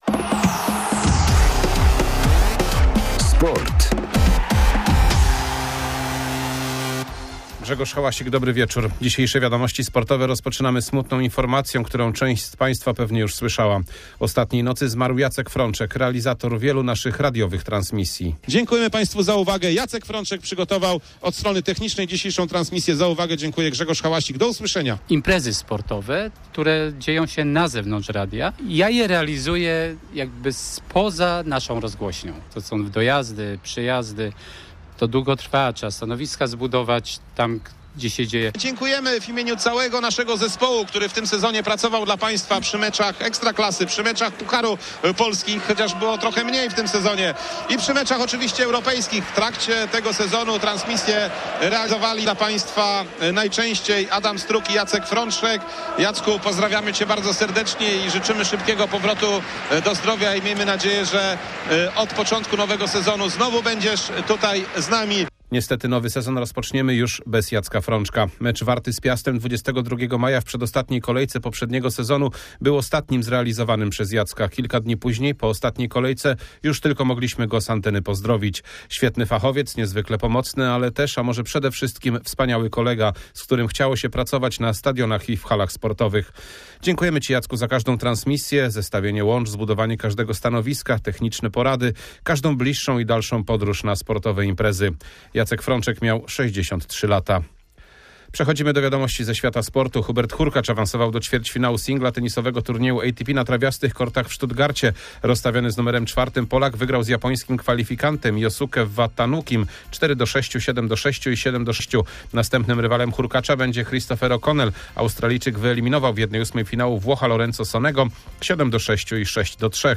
14.06.2023 SERWIS SPORTOWY GODZ. 19:05